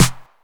909snr.wav